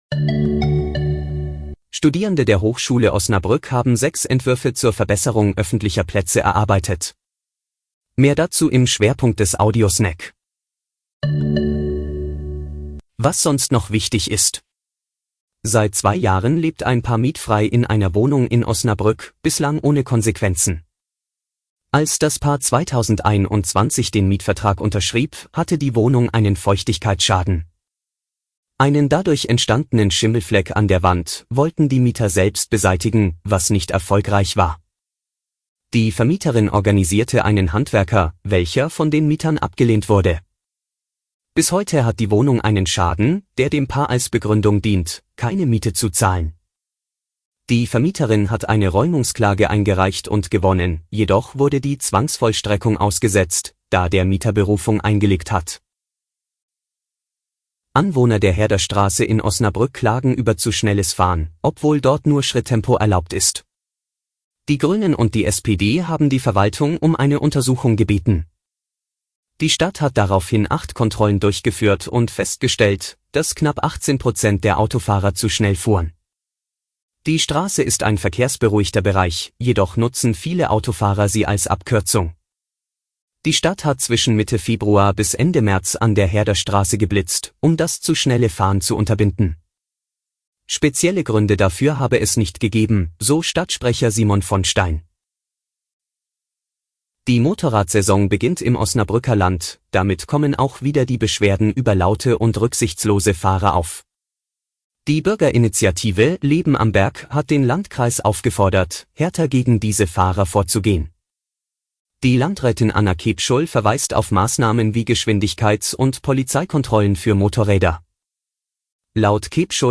Täglich regionale News zum Hören